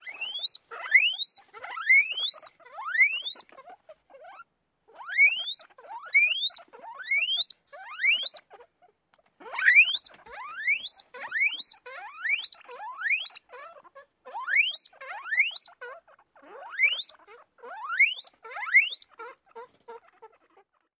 Звук визжащей или свистящей морской свинки